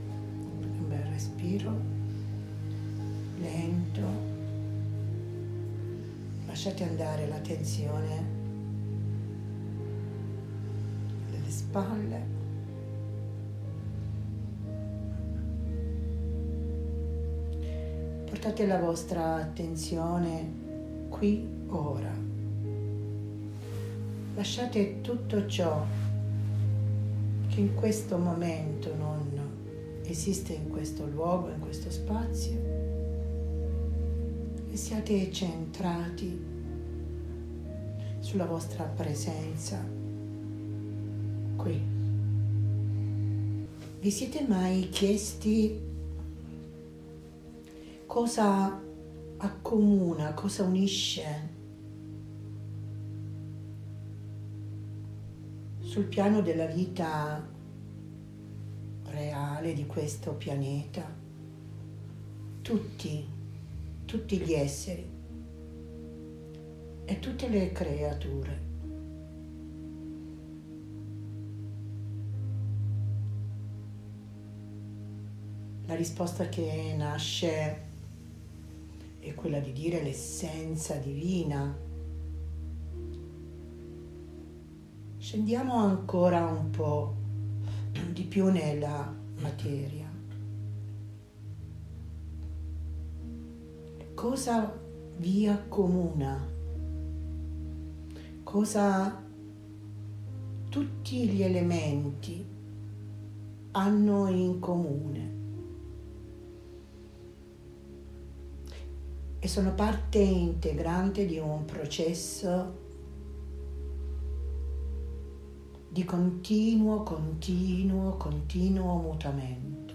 cerimonia-della-luce-aprile-2022.mp3